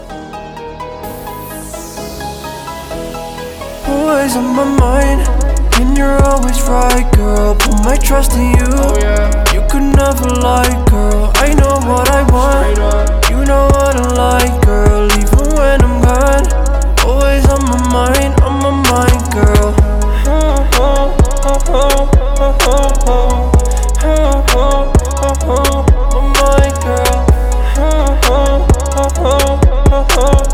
• Качество: 320, Stereo
Хип-хоп
спокойные
Bass